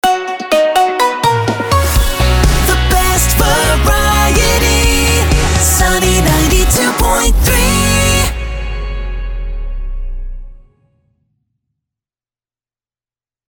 Best Variety Sweeper FacebookPinterestTwitterLinkedin
Best-Variety-Sweeper.mp3